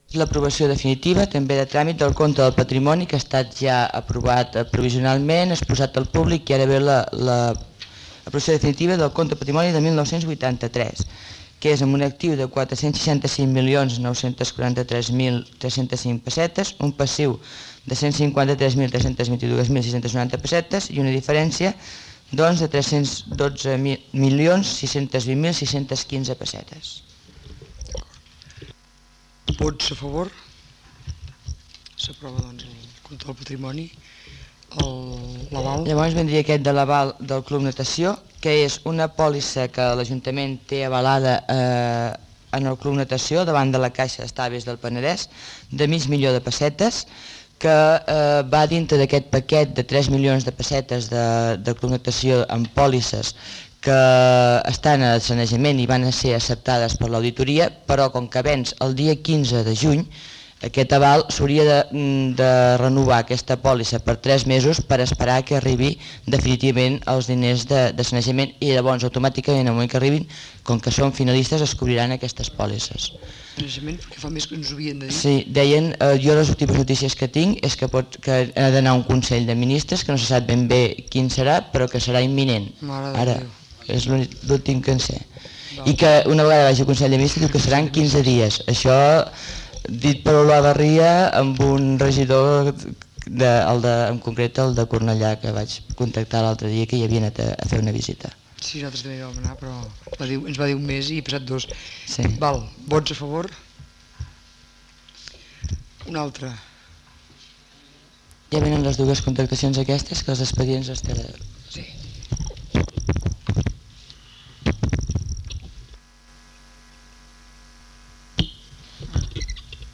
Transmissió del Ple Municipal de l'Ajuntament de Molins de Rei
Informatiu